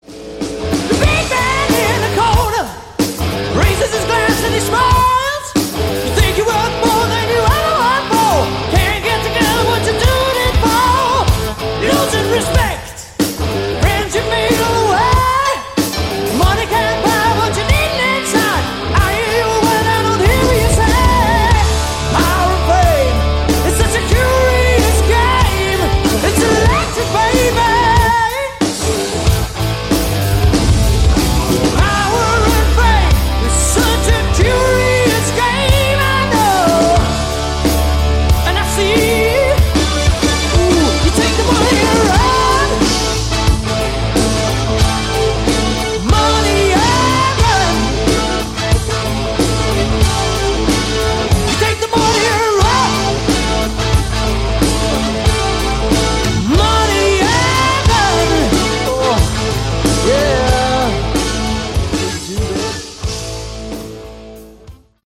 Category: Hard Rock
keyboards
drums